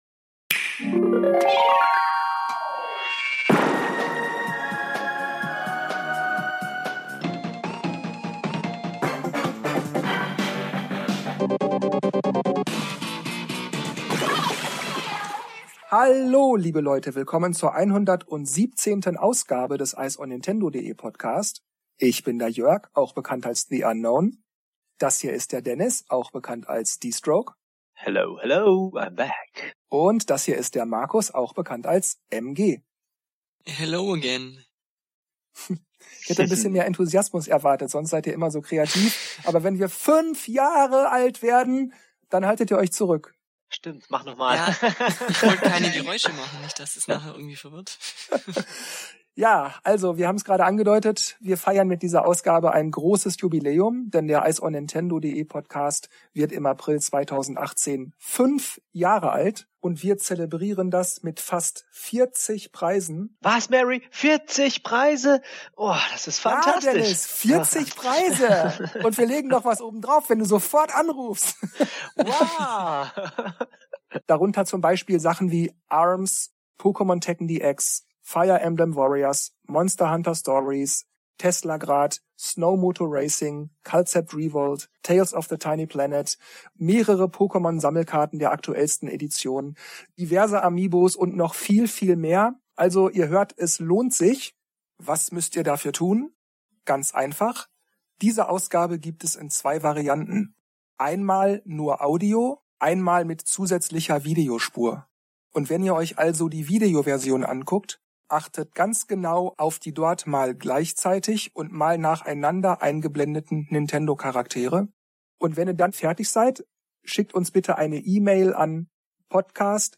/ [Dauer: 0:33:19] 0:35:55 = Fazits / [Dauer: 0:02:51] 0:38:46 = Verabschiedung / [Dauer: 0:01:58] Verknüpfte Tags Talk News Gerüchte Ankündigungen Gewinnspiel Switch Diese Ausgabe abspielen auf...